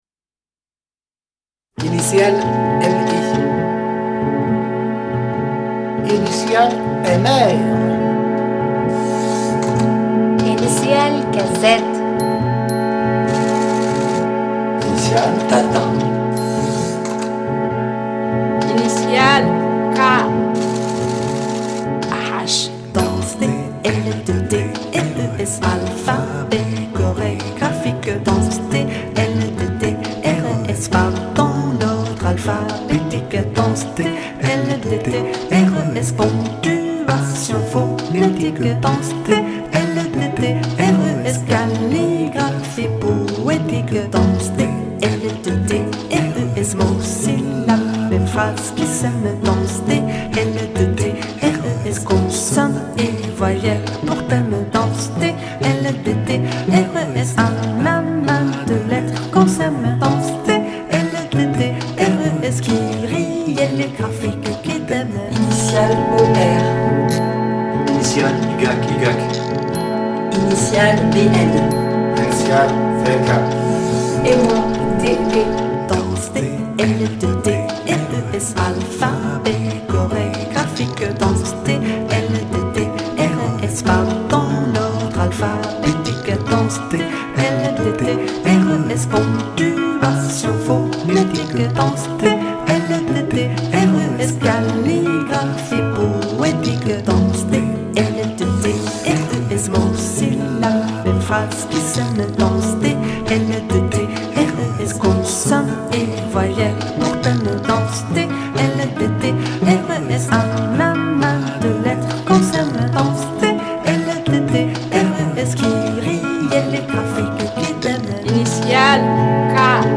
5 violoncelles Machine à écrire 3 guitares Paroles